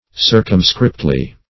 Search Result for " circumscriptly" : The Collaborative International Dictionary of English v.0.48: Circumscriptly \Cir"cum*script`ly\, adv.